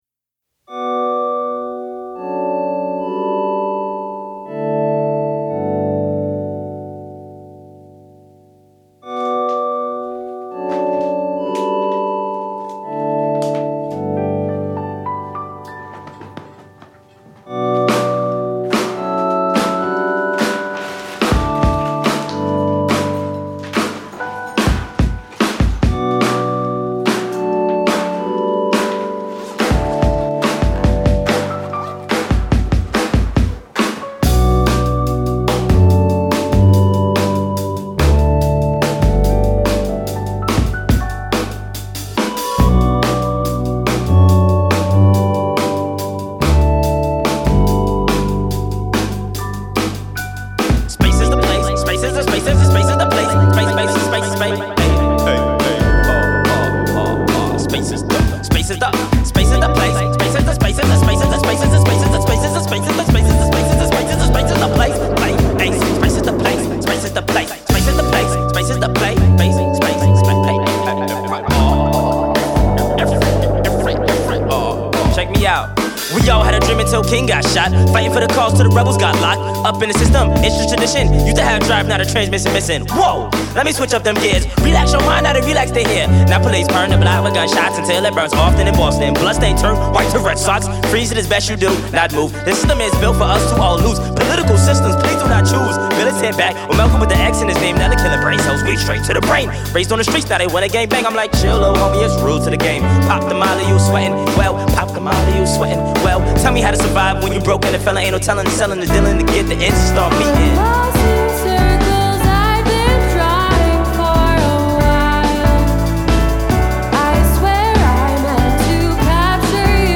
With their infectious soul/hip-hop tracks